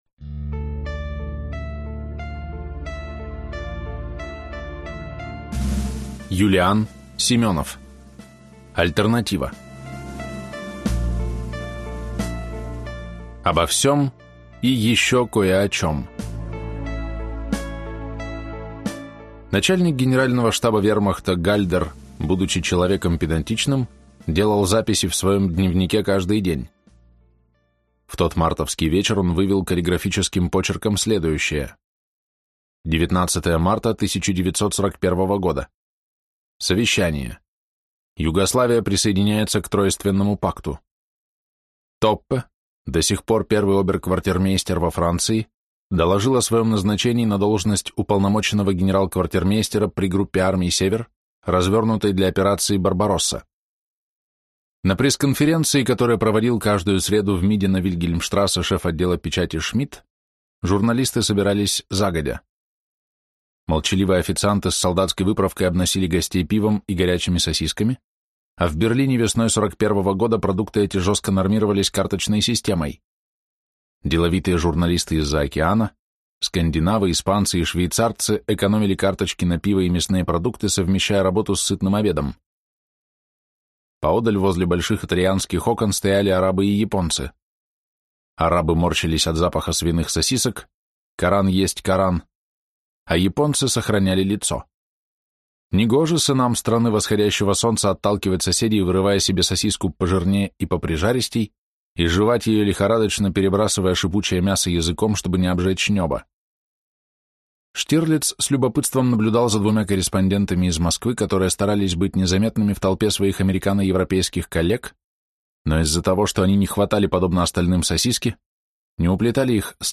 Исаев-Штирлиц. Книга 5. Альтернатива (слушать аудиокнигу бесплатно) - автор Юлиан Семенов